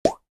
th_sfx_pop_2.ogg